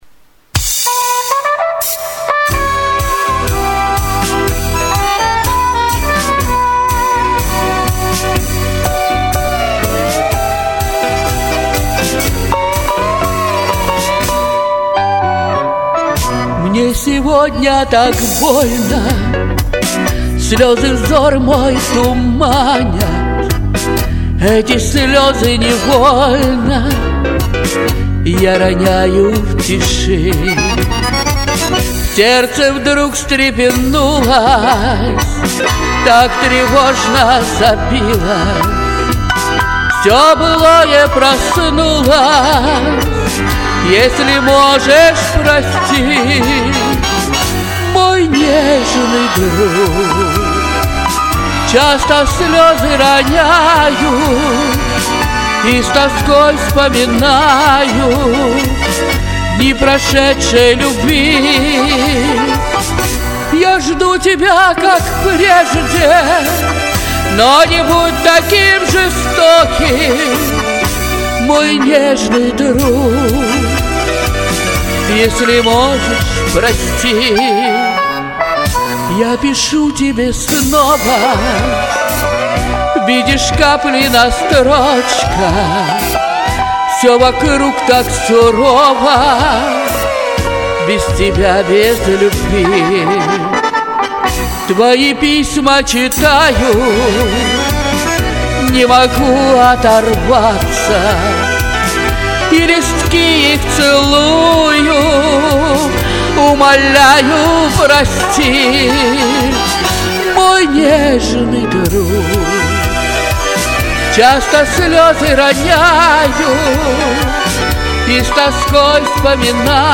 Вот все есть в голосе - и свобода, и страсть, и полет...